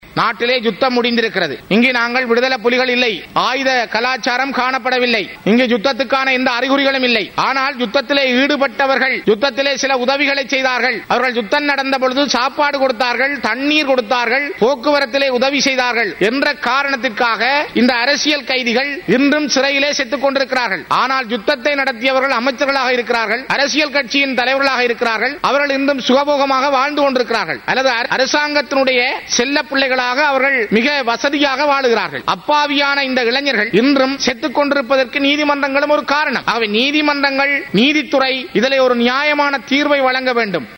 மேல் நீதிமன்ற மற்றும் உயர் நீதிமன்ற நீதிபதிகளின் வேதன உயர்வு தொடர்பில் நீதி அமைச்சர் விஜயதாச ராஜபக்ஷவினால் கொண்டுவரப்பட்ட பிரேரணை தொடர்பான வாத விவாதங்கள் நேற்று நாடாளுமன்றில் இடம்பெற்றது.
இதில் கலந்து கொண்டு உரையாற்றிய போதே அவர் இந்த கோரிக்கையை முன்வைத்தார்.